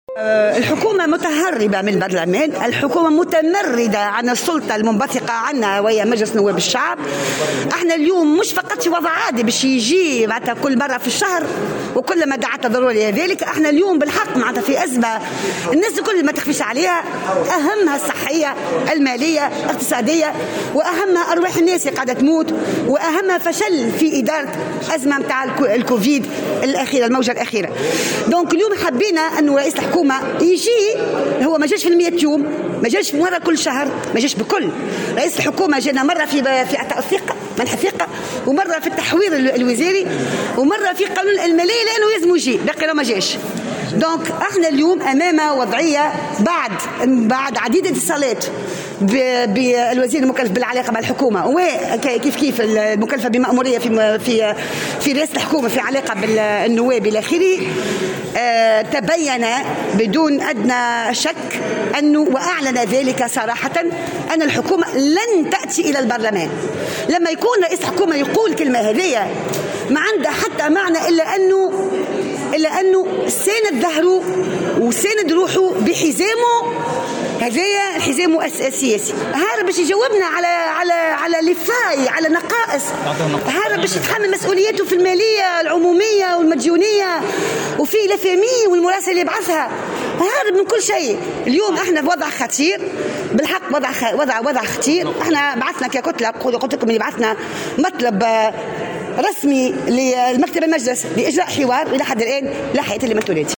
وأضافت في تصريح لمراسل "الجوهرة أف أم" اليوم أن المشيشي تعمّد عدم الحضور في عديد المرات ورفض الحوار حول الوضع الصحي والاقتصادي والاجتماعي رغم توجيه دعوة رسمية في الغرض لمكتب المجلس.